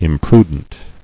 [im·pru·dent]